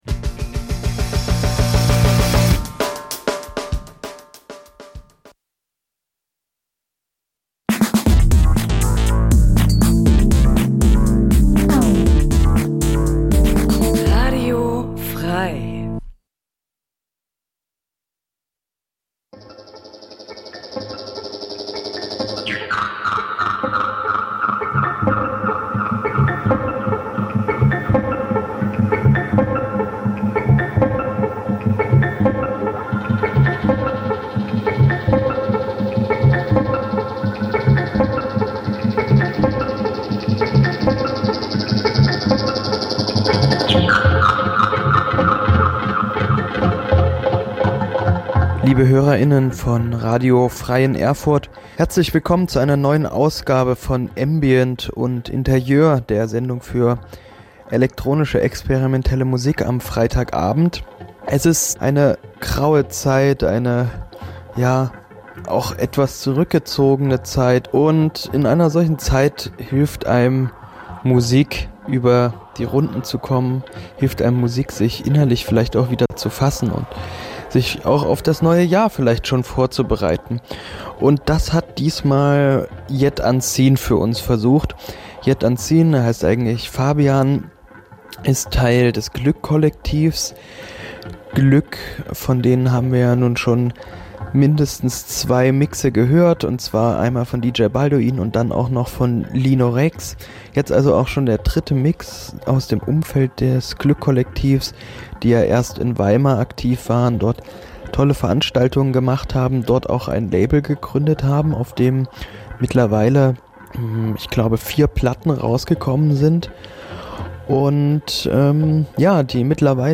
Ambient-Musik, oftmals als Fahrstuhlmusik, Hintergrundgedudel oder Kaufhausmusik diskreditiert, er�ffnet uns, bei genauerem Hinblick, eine neue Form des musikalischen Erlebnisses. Die meist ruhigen und getragenen elektronischen Kl�nge, der entschleunigte Charakter dieser Musikrichtung, erfordern ein �bewusstes Sich-Einlassen� auf die Musik, einen quasi meditativen Akt, der als musikalische Alternative zur modernen Leistungsgesellschaft gesehen werden kann. Grund genug, diesem Musikkonzept in einer Musiksendung Raum zu schaffen.